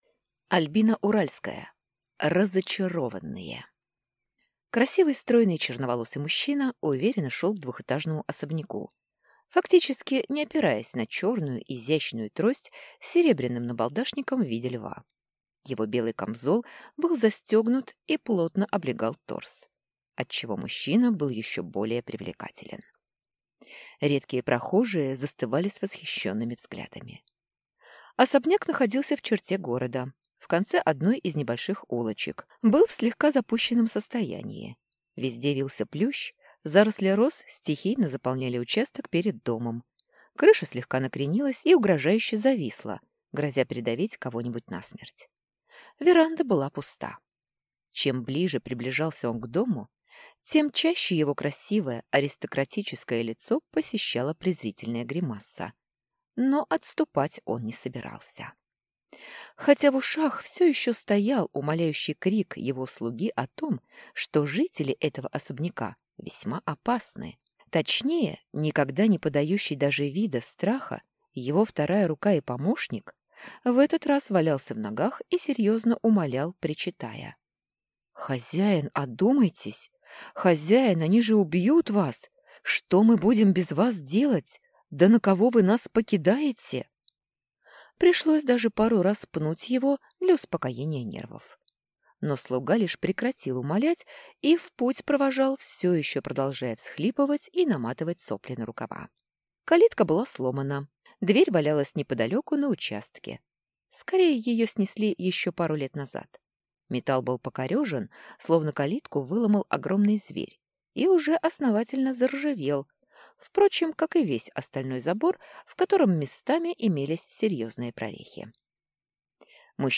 Аудиокнига РазАчарованные | Библиотека аудиокниг